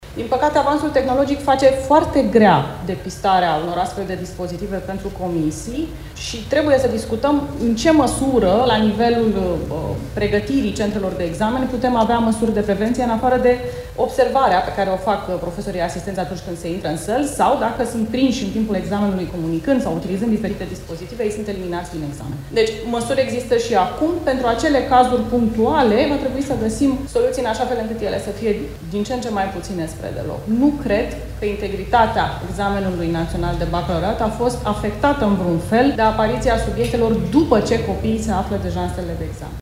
Ligia Deca – ministrul Educației: „Nu cred că integritatea examenului național de Bacalaureat a fost afectată în vreun fel de apariția subiectelor după ce copiii se află deja în sălile de examen”